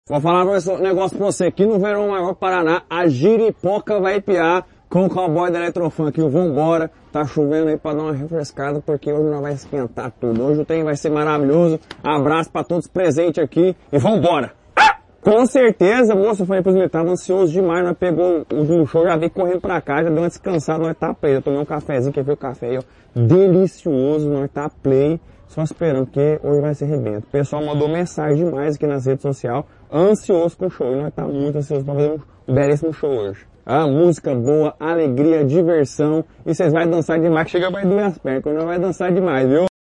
Sonora do DJ Jiraya Uai sobre a apresentação no Verão Maior Paraná